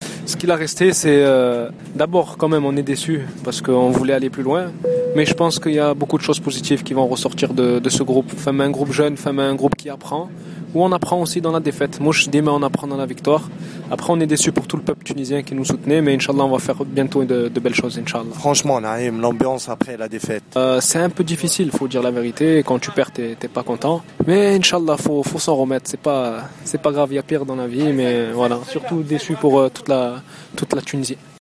نعيم السليتي: لاعب المنتخب الوطني